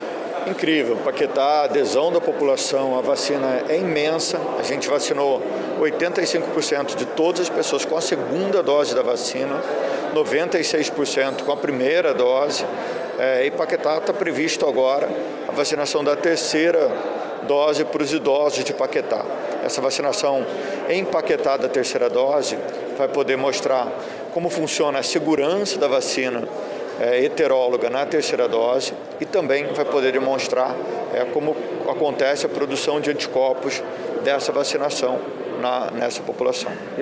O secretário municipal de saúde, Daniel Soranz, durante a inauguração do posto de vacinação no Palácio Pedro Ernesto, Sede da Câmara Municipal, na Cinelândia, no Centro do Rio, disse que uma terceira dose de vacina deve ser aplicada aos idosos.